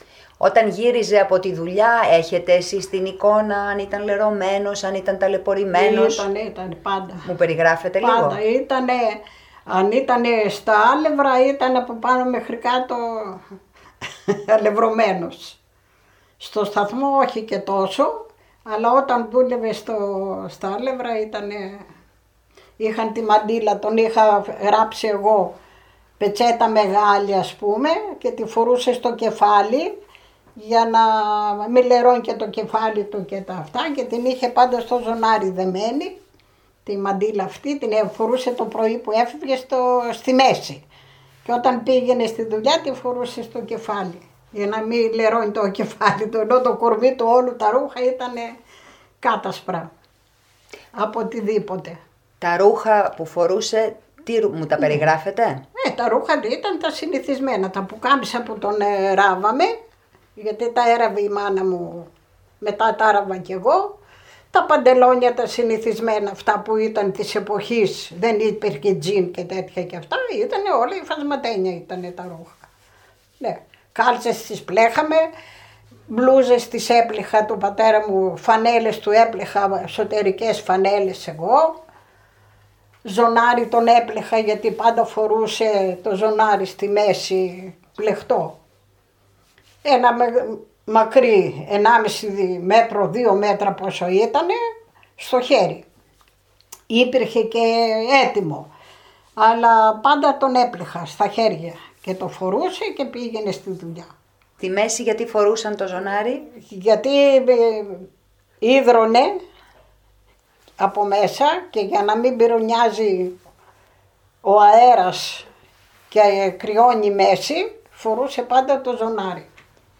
Απόσπασμα συνέντευξης